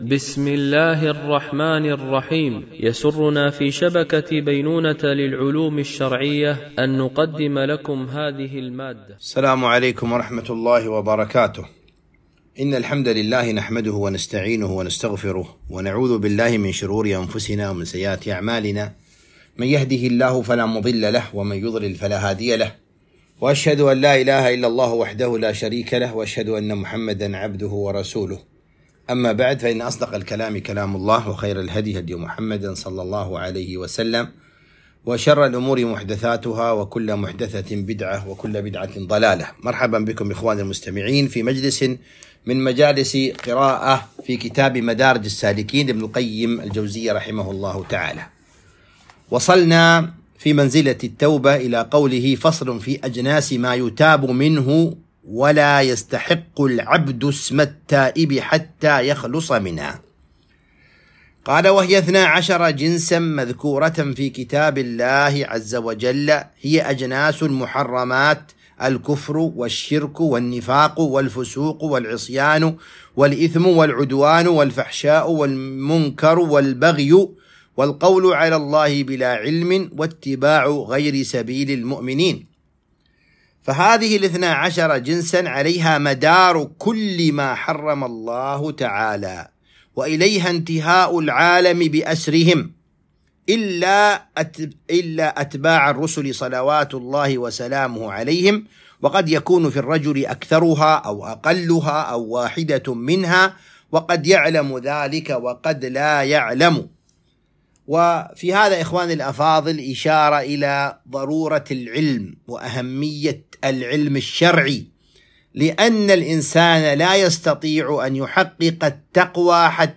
قراءة من كتاب مدارج السالكين - الدرس 36